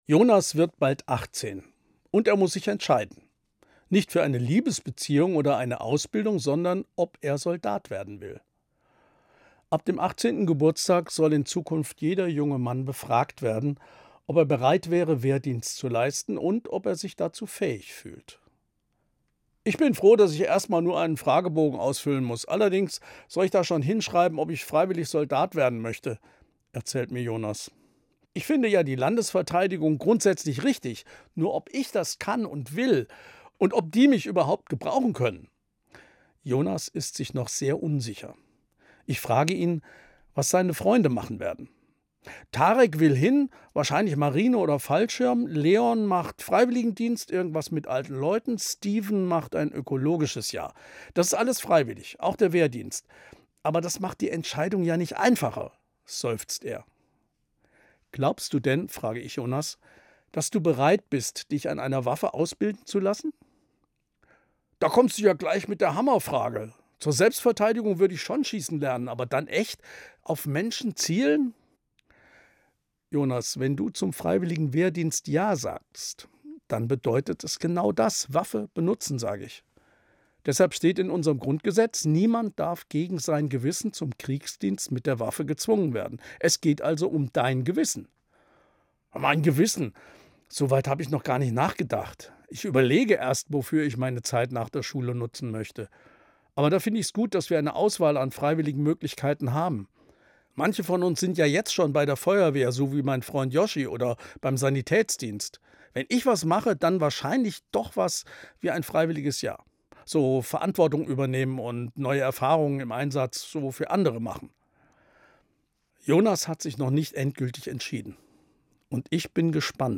Evangelischer Pfarrer, Kassel